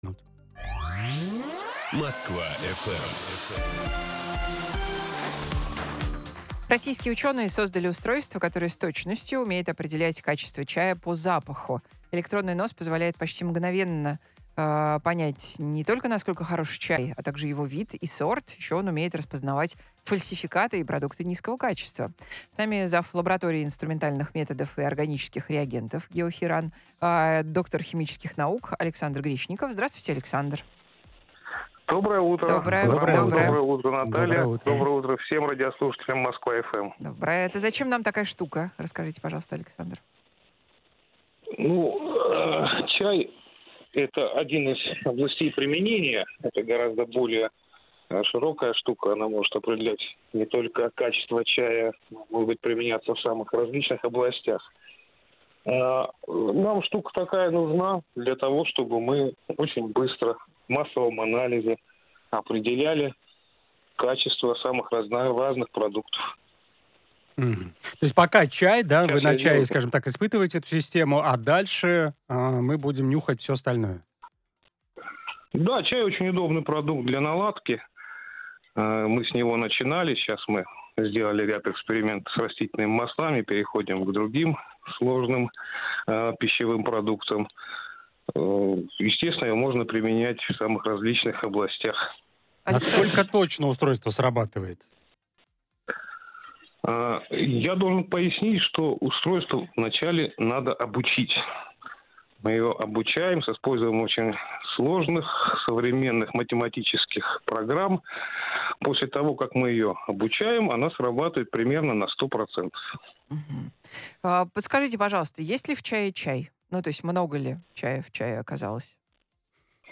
рассказал на радио "Москва FM" об одном из возможных применении "масс-спектрометрического носа" - для определения качества чая по запаху (эфир от 28.12.2024, 8:10).